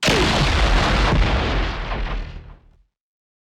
boss死亡.wav